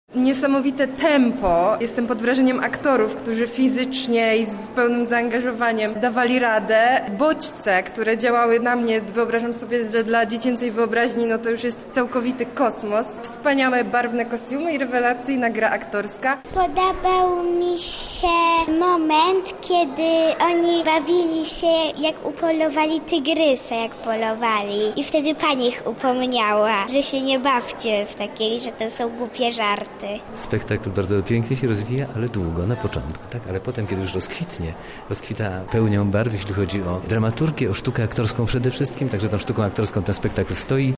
Po spektaklu emocje wśród widzów sprawdzał